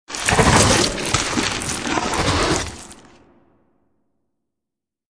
Sfx_animation_luxtor_win.ogg